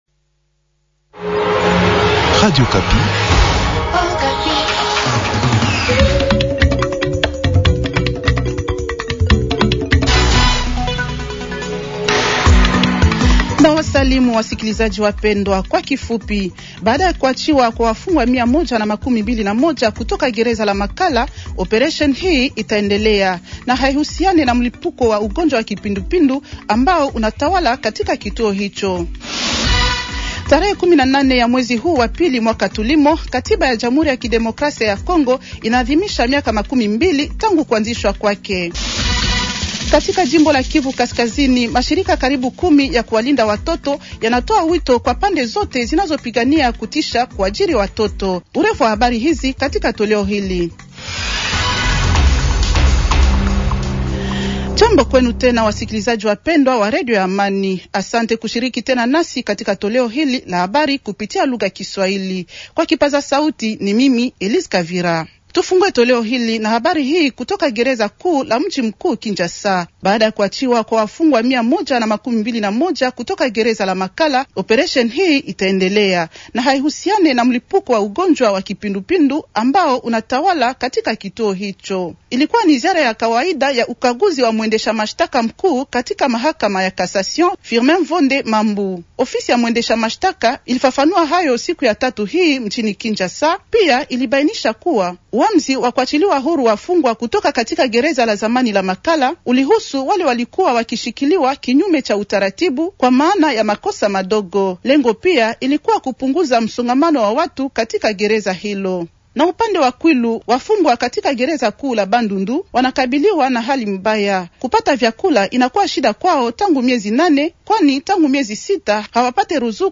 Journal Swahili
Habari za asubuhi za tarehe 190226